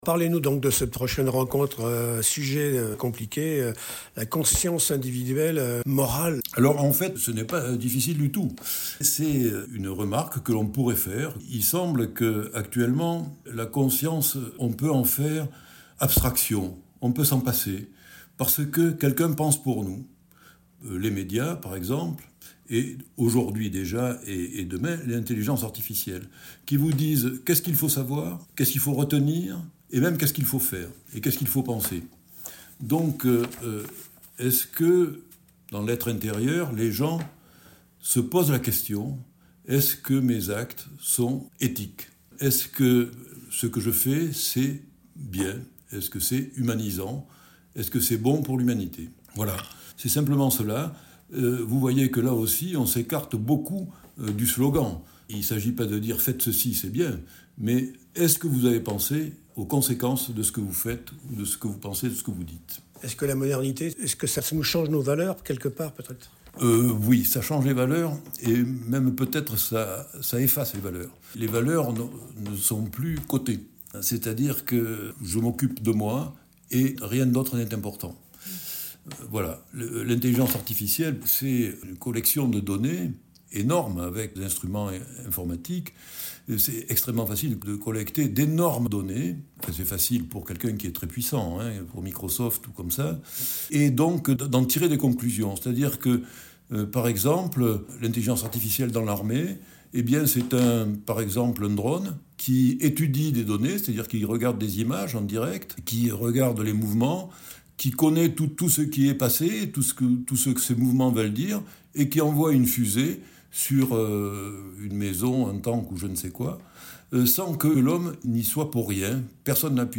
– Interview audio…